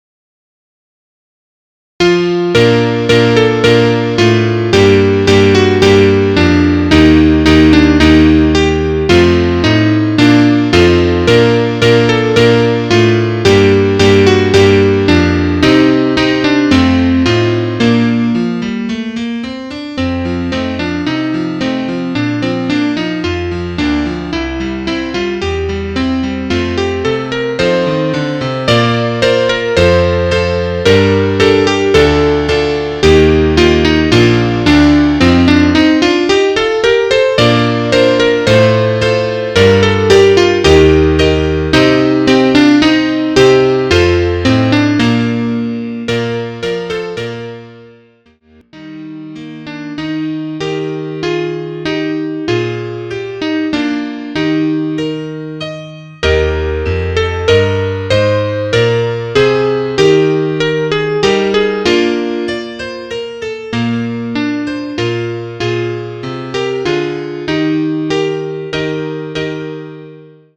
Etüde für ein Soloinstrument mit Klavierbegleitung